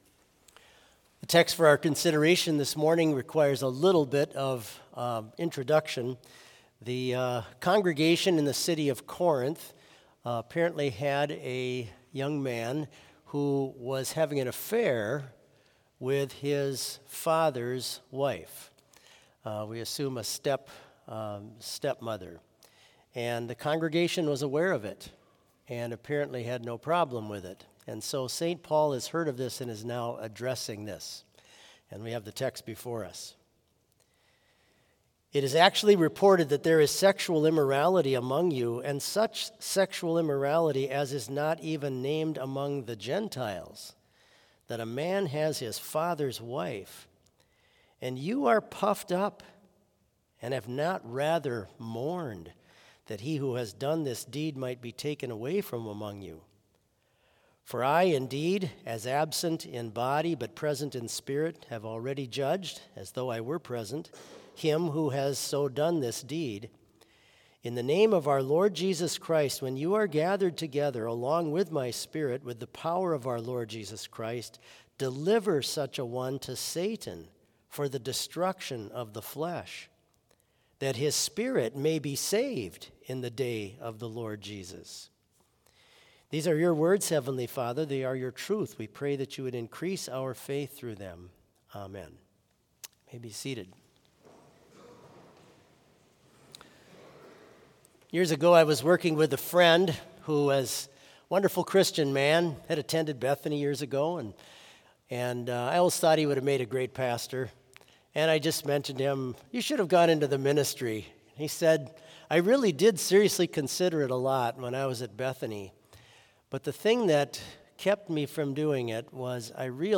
Complete service audio for Chapel - Friday, October 6, 2023